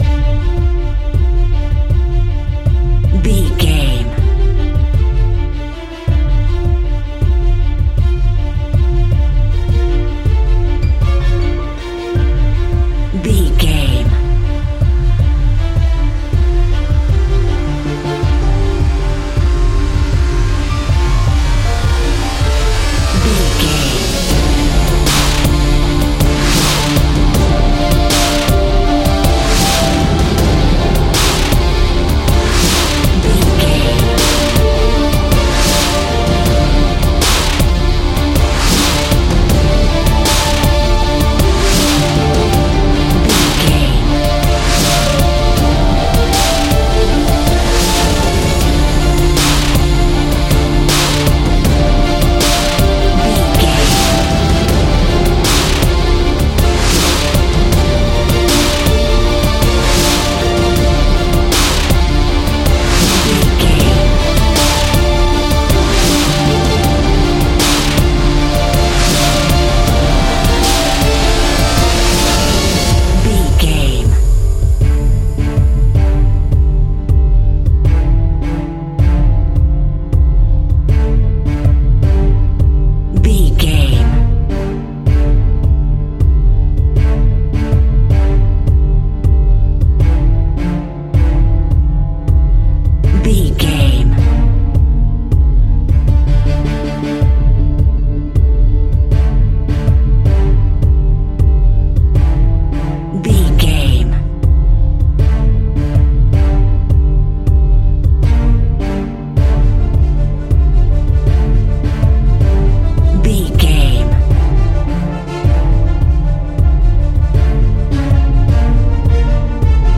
Aeolian/Minor
strings
percussion
synthesiser
brass
cello
double bass